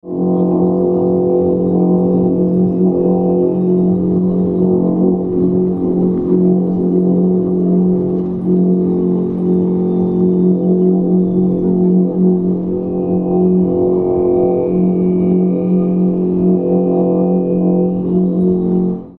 WIND GOM-GOM
Le vent soufflant sur le ruban provoque une vibration de celui-çi et par conséquent du fil inox aussi.
Le Wind Gom-Gom produit un son bas, propice à la méditation , beaucoup plus bas que les autres instruments éoliens. Il démarre avec un vent faible à médium et supporte les turbulences.
It's a compound instrument between the aeolian harp and the aeolian musical bow : a short part of the string is a ribbon (thin metal ribbon used for meter measure, 50 cm long, 5 mm wide) and the long part is a stainless metal thread (fishing stainless metal thread, 0.3 mm section, about 1 m long).
A resonator amplifies the vibration : I prefer a calebass with a natural skin as membrane in order to filter the bass tone.